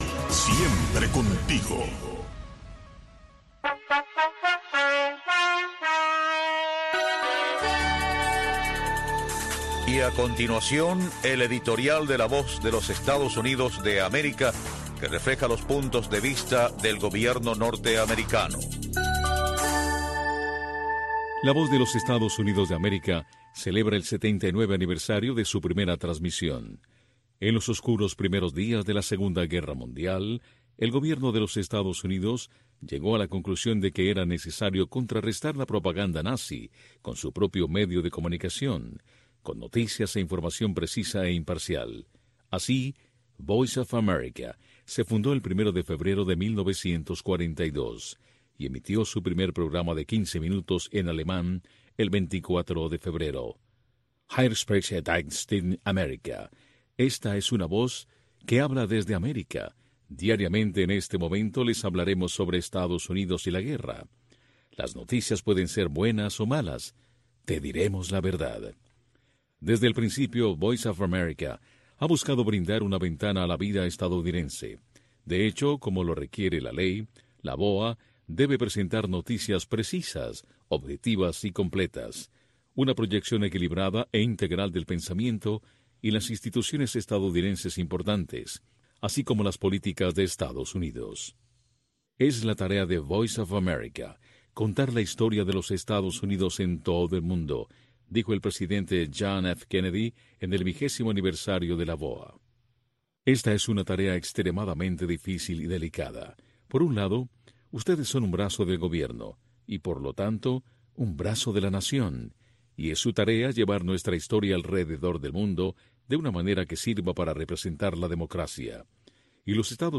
La Santa Misa
PROGRAMACIÓN EN-VIVO DESDE LA ERMITA DE LA CARIDAD